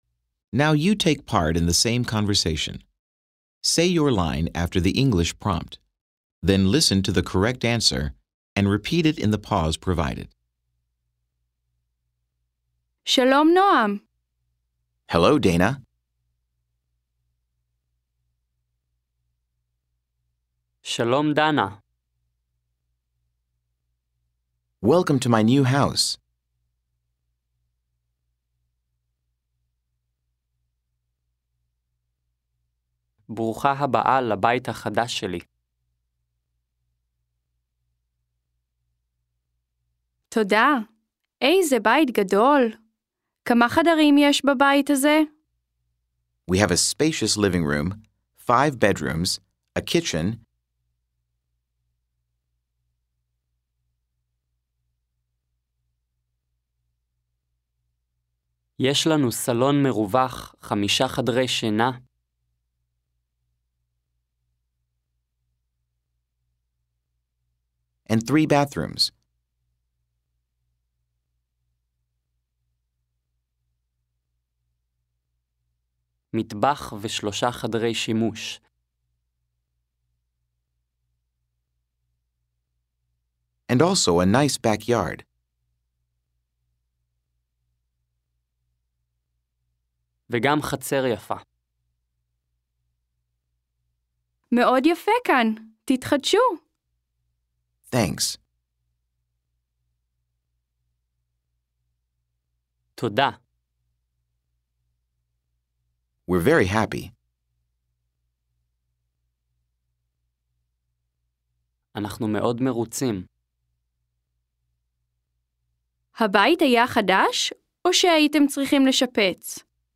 13 Dialogue 2c.mp3